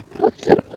Minecraft Version Minecraft Version 25w18a Latest Release | Latest Snapshot 25w18a / assets / minecraft / sounds / mob / endermen / idle3.ogg Compare With Compare With Latest Release | Latest Snapshot